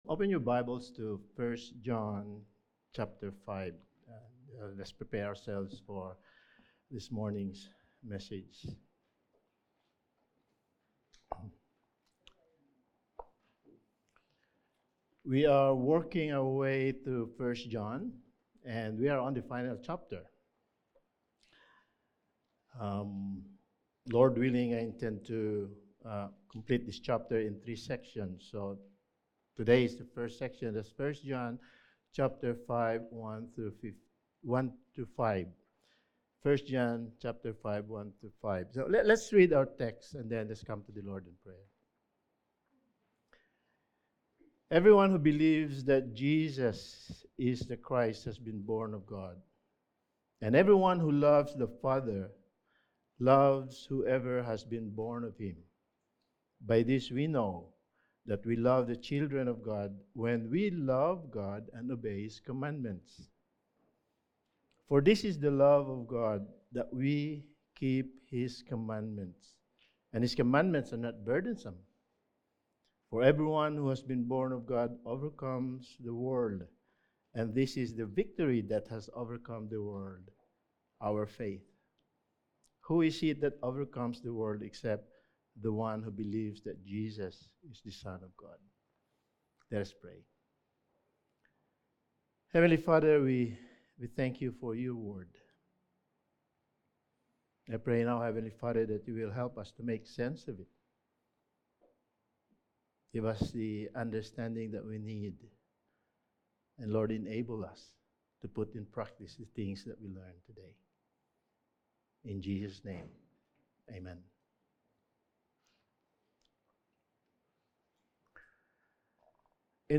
1 John Series – Sermon 15: The Overcomers of the World
Service Type: Sunday Morning